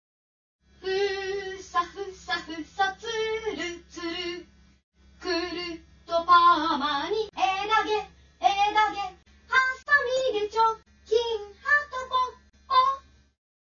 絵描き歌はとポッポ